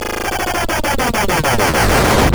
Glitch FX 30.wav